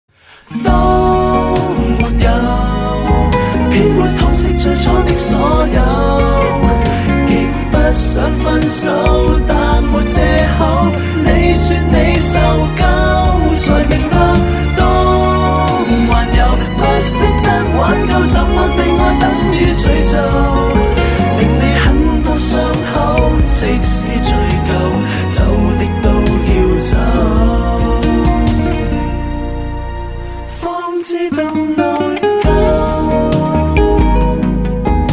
M4R铃声, MP3铃声, 华语歌曲 69 首发日期：2018-05-14 12:48 星期一